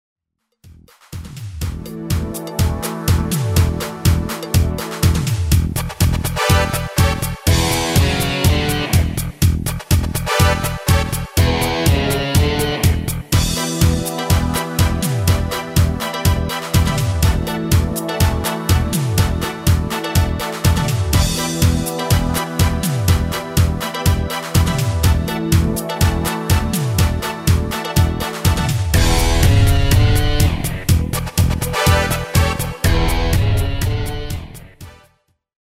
Demo/Koop midifile
Genre: Disco
- Géén vocal harmony tracks